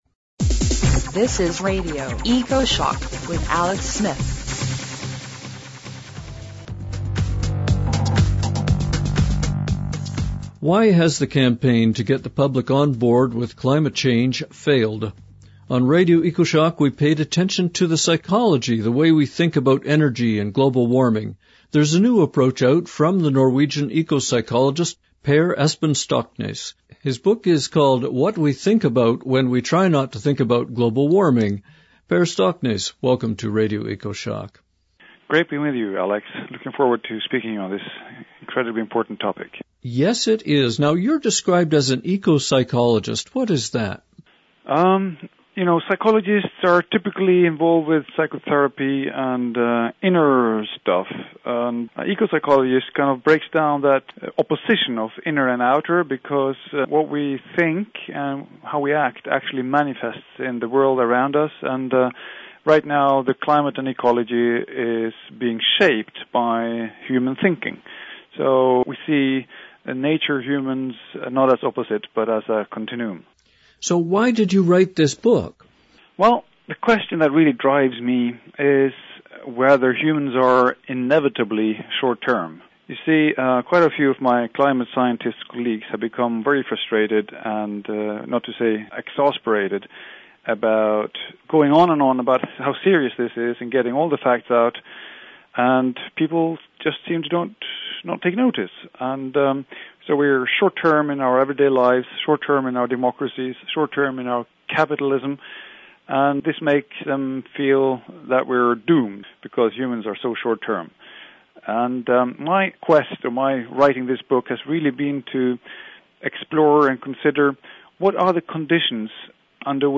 * Norwegian eco-psychologist Per Espen Stoknes tells us why public concern about climate may be falling, even as the science becomes more certain.